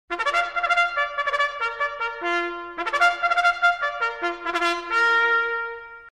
First-Call-1.mp3